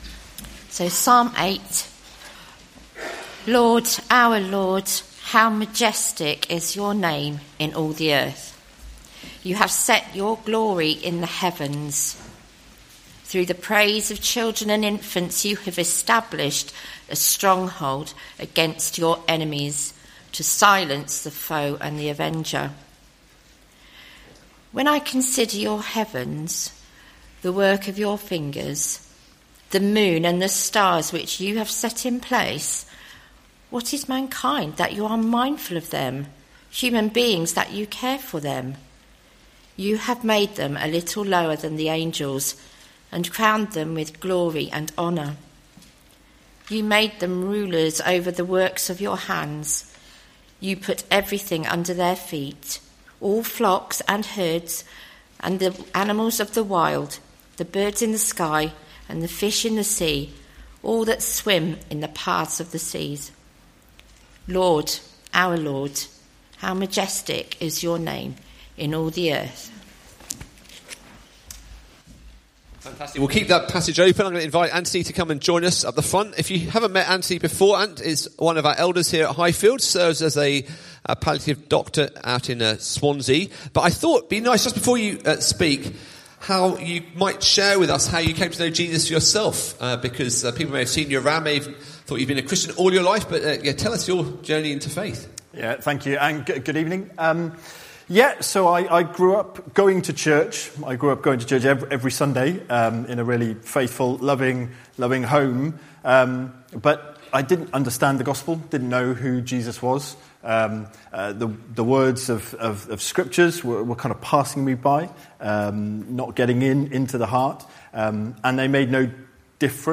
Psalm 8; 19 January 2025, Evening Service. Sermon Series